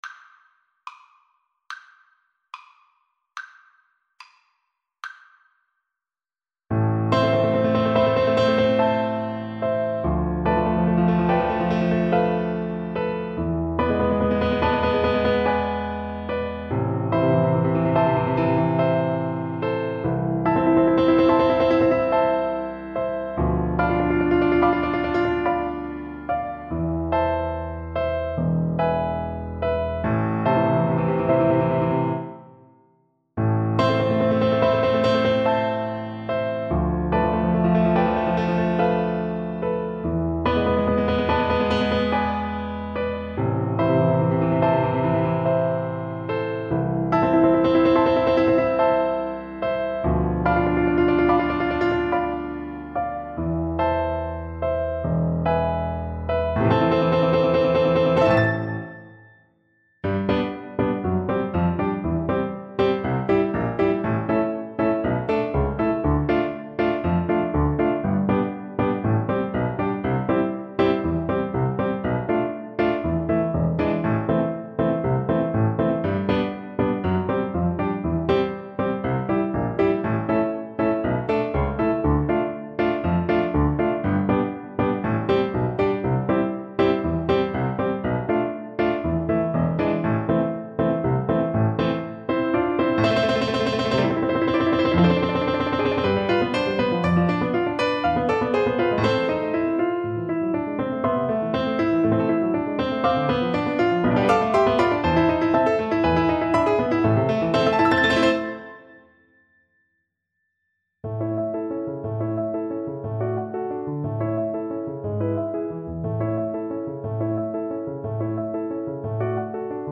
2/4 (View more 2/4 Music)
Poco sostenuto =c.72
Classical (View more Classical Violin Music)